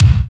27.06 KICK.wav